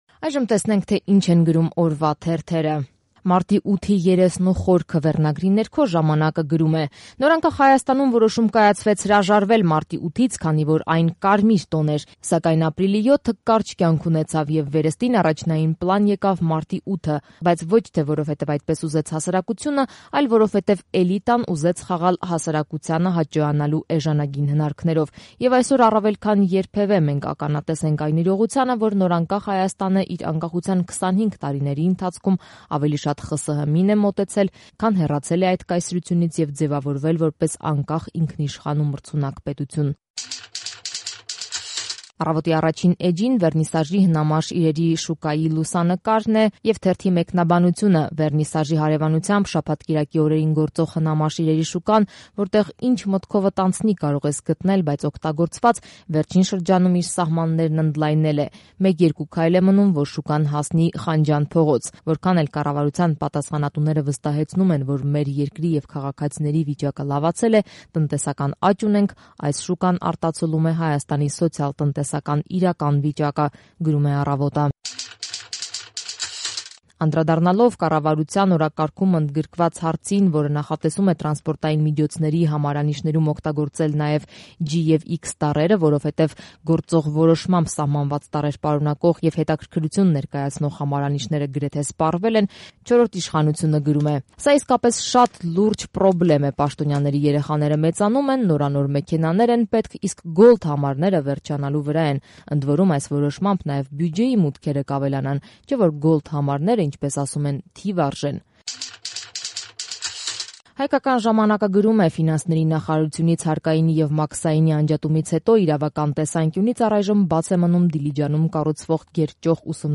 Մամուլի տեսություն